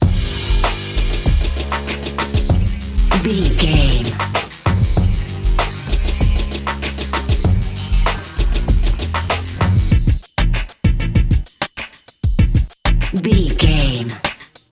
Aeolian/Minor
synthesiser
drum machine
funky
aggressive
hard hitting